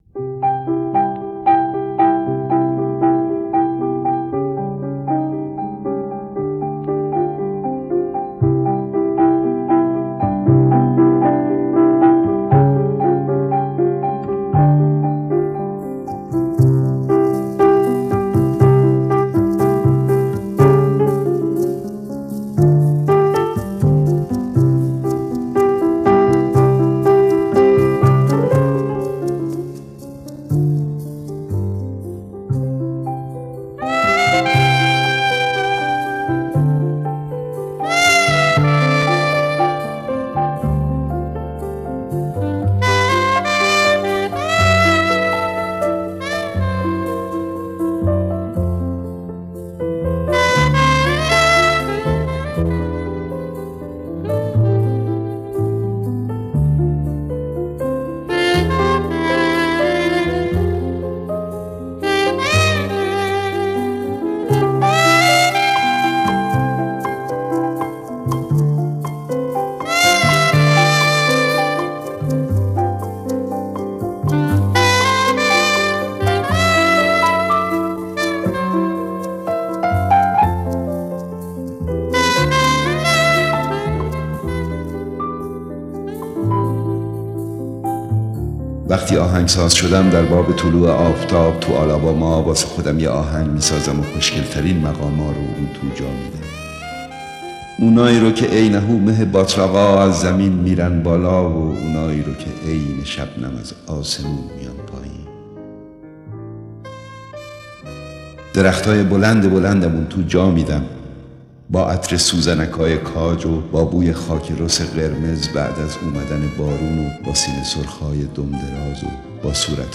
دانلود دکلمه وقتی آهنگساز شدم با صدای احمد شاملو
گوینده :   [احمد شاملو]